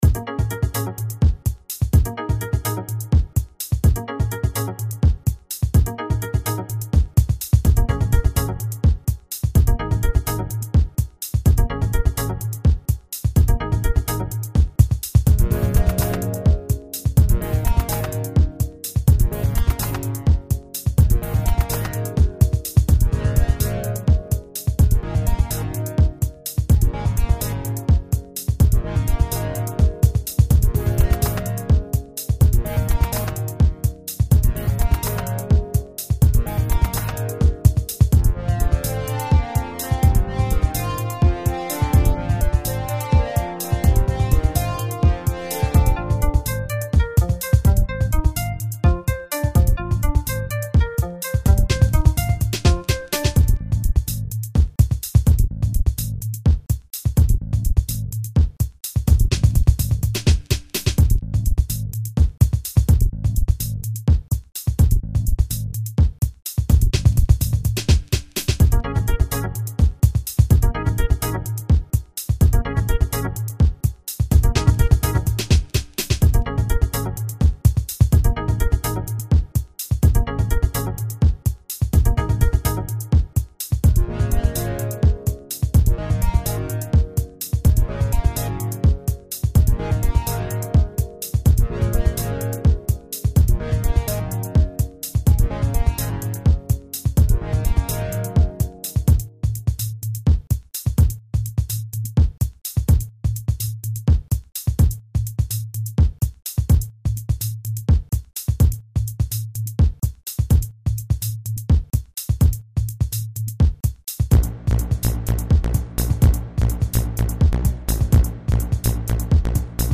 ポップテクノ調 ビートが弱い・・・それだけが残念（−−；；；
途中からゴリゴリしたサウンドが入ってきますがまあこれも実験の一部。